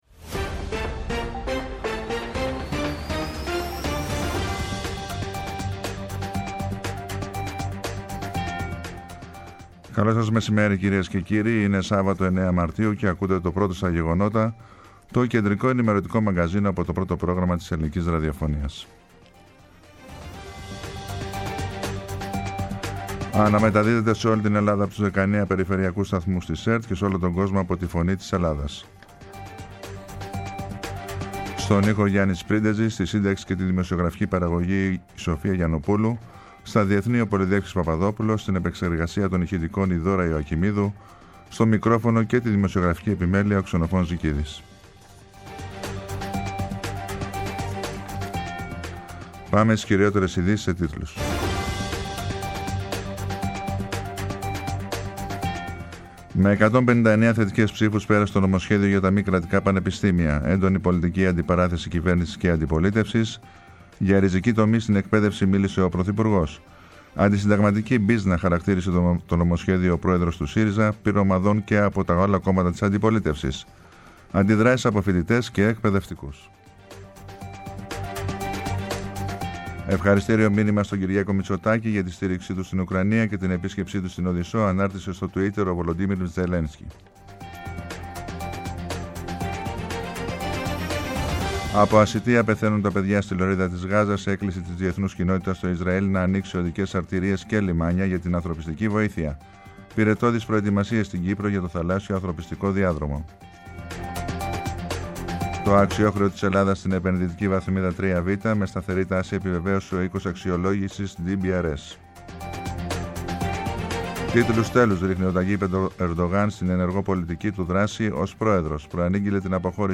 Με το μεγαλύτερο δίκτυο ανταποκριτών σε όλη τη χώρα, αναλυτικά ρεπορτάζ και συνεντεύξεις επικαιρότητας. Ψύχραιμη ενημέρωση, έγκυρη και έγκαιρη.